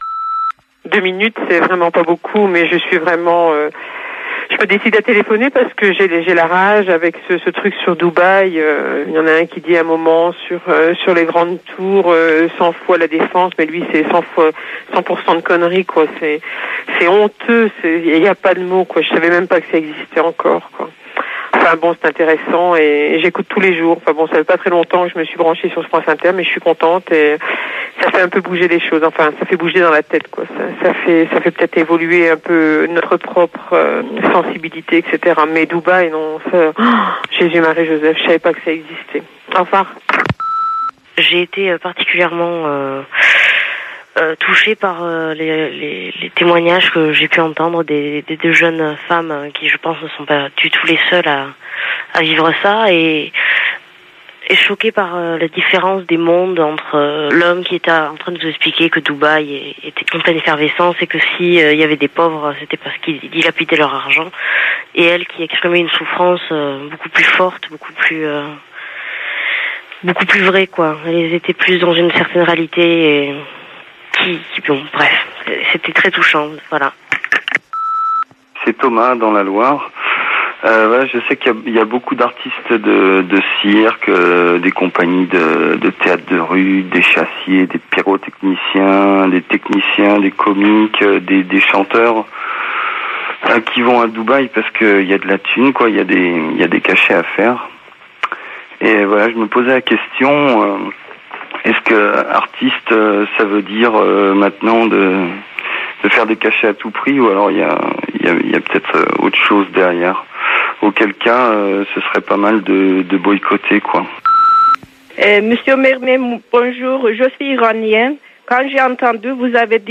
Ce reportage a été diffusé dans l'émission Là-bas si j'y suis de France Inter, le 2 juin 2006.
Reportage à Grenoble de l'émission Là-bas si j'y suis